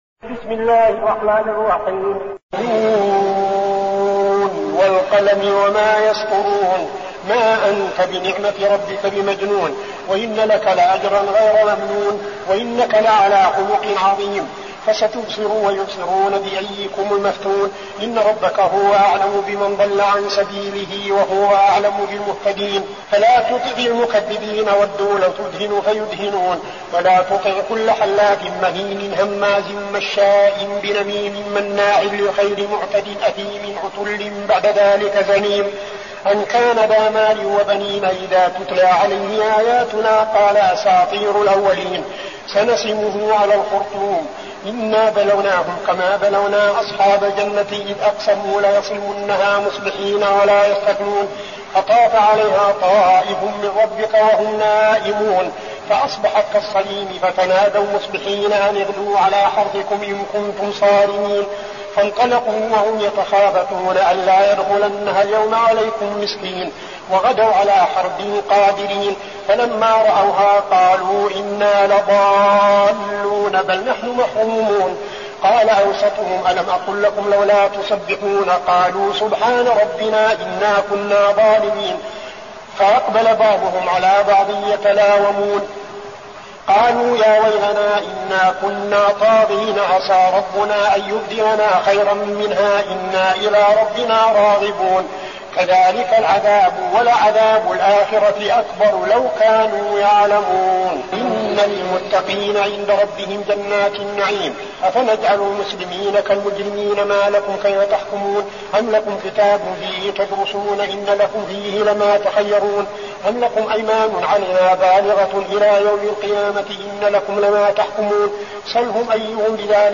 المكان: المسجد النبوي الشيخ: فضيلة الشيخ عبدالعزيز بن صالح فضيلة الشيخ عبدالعزيز بن صالح القلم